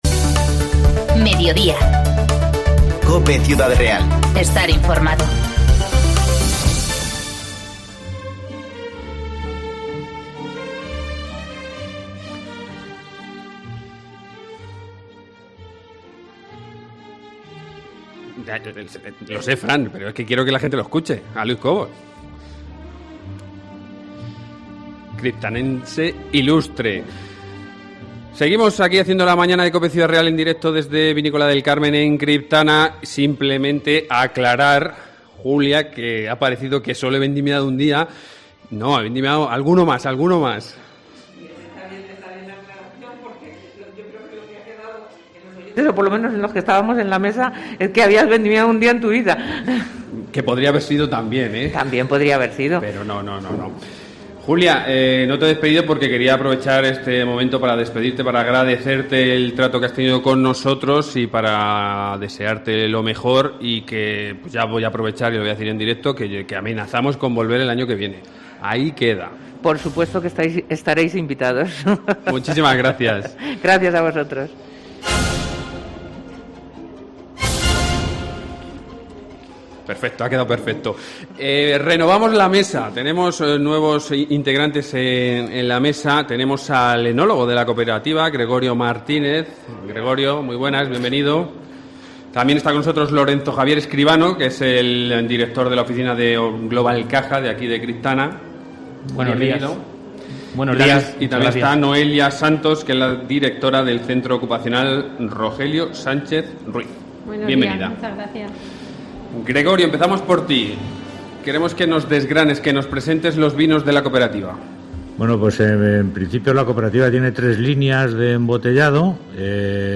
La Mañana de COPE Ciudad Real desde la Cooperativa Vinícola del Carmen, de Campo de Criptana (segunda parte)